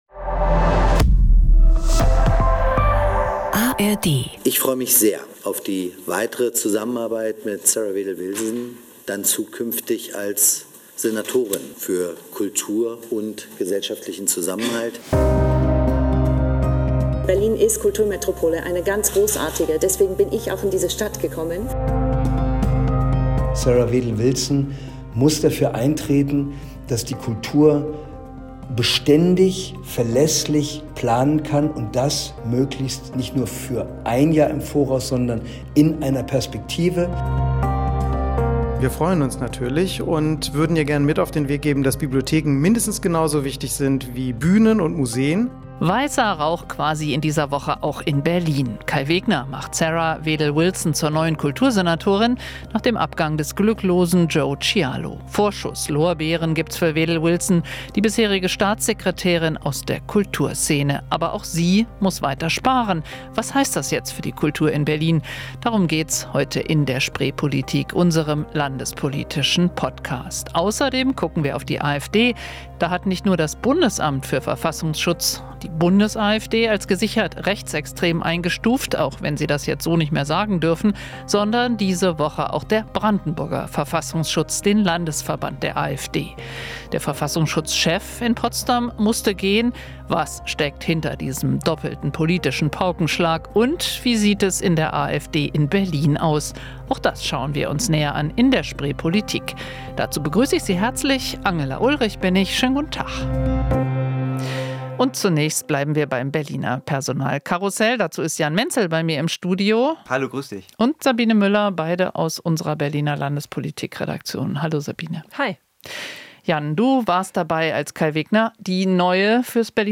(Hinweis: Diese Folge endet mit einem O-Ton der Holocaust-Überlebenden Margot Friedländer und wurde vor der Nachricht ihres Todes im Alter von 103 Jahren aufgezeichnet.) "Weißer Rauch" auch in Berlin in dieser Woche: Nach dem Abgang des glücklosen Joe Chialo hat der Regierende Bürgermeister Kai Wegner Sarah Wedl-Wilson zur Kultursenatorin ernannt.
Jede Woche spricht das Team der rbb-Redaktion Landespolitik über die großen, kleinen und besonderen Themen aus Berlin und Brandenburg.